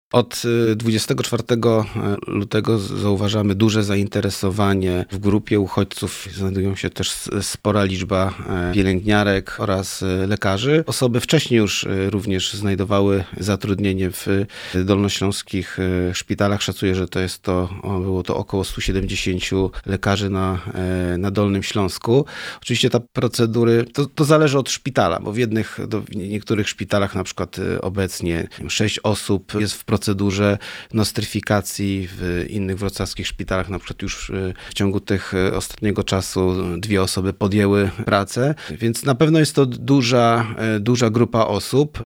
Mówi Marcin Krzyżanowski – Wicemarszałek Województwa Dolnośląskiego.